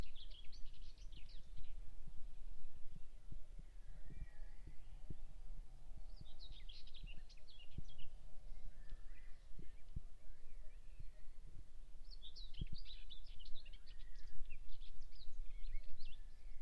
Whitethroat, Curruca communis
Administratīvā teritorijaAlūksnes novads
StatusSinging male in breeding season